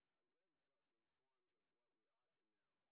sp25_street_snr20.wav